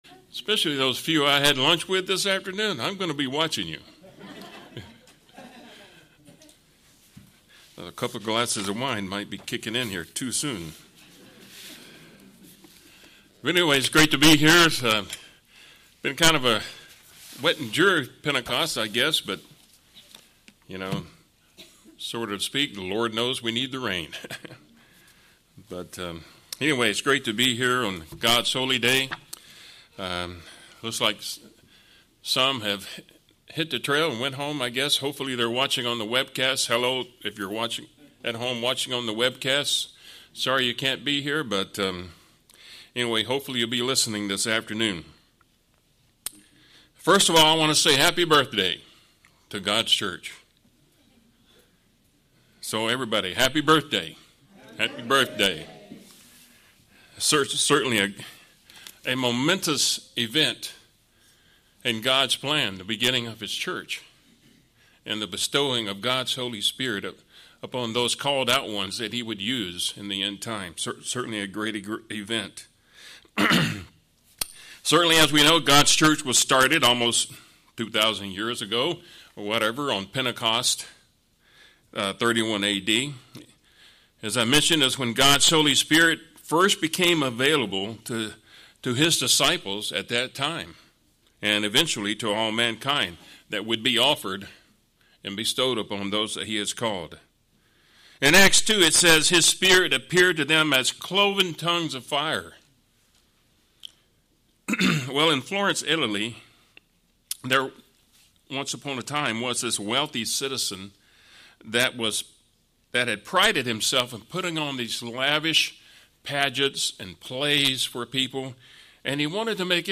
Webcast Sermons
Given in St. Petersburg, FL